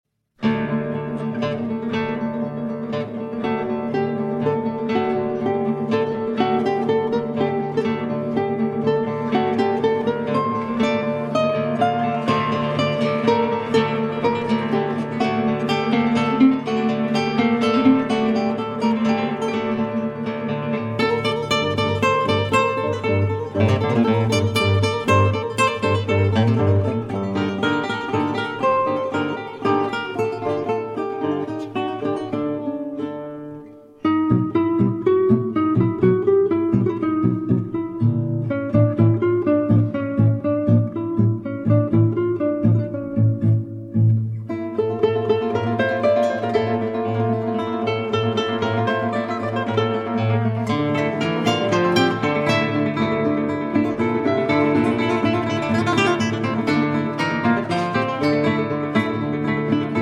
3 Guitars